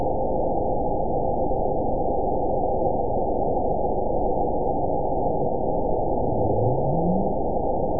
event 911390 date 02/24/22 time 17:37:57 GMT (3 years, 2 months ago) score 9.64 location TSS-AB04 detected by nrw target species NRW annotations +NRW Spectrogram: Frequency (kHz) vs. Time (s) audio not available .wav